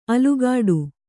♪ alugāḍu